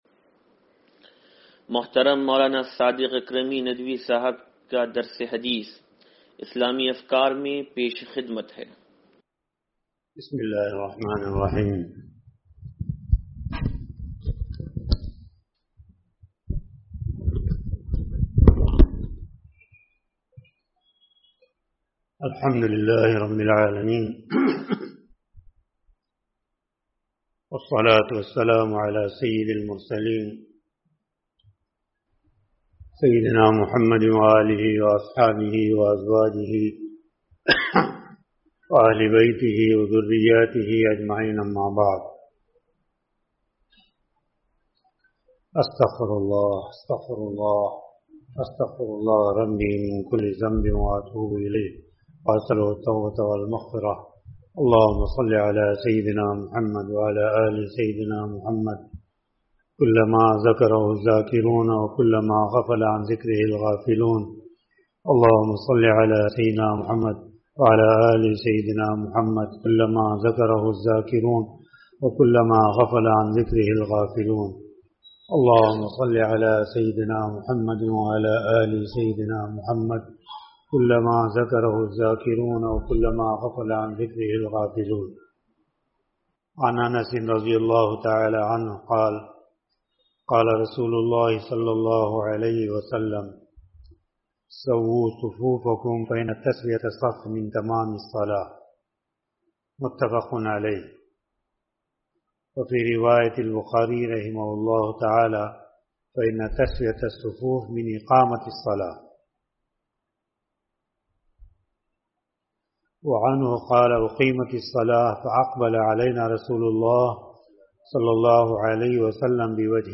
درس حدیث نمبر 0824
سلطانی مسجد